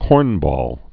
(kôrnbôl) Slang